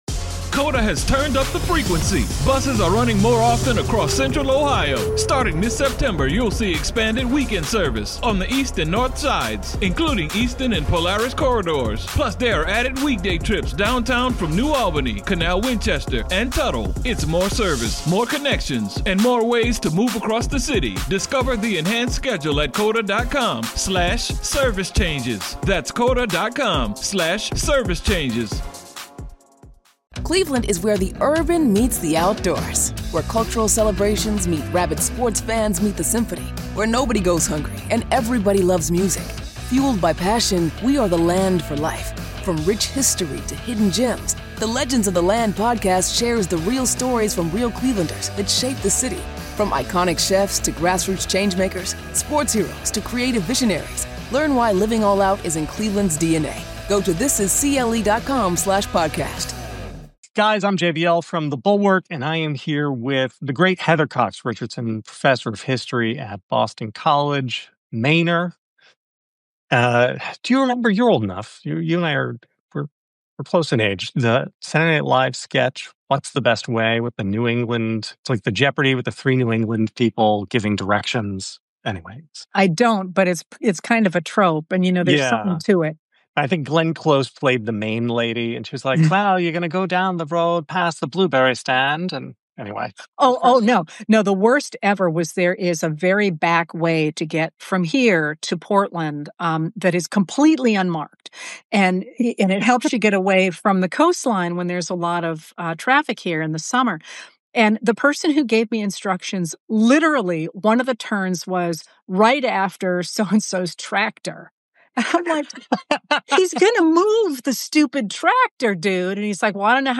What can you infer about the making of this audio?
NOTE: This livestream was recorded on Wednesday, September 17th at 3pm ET.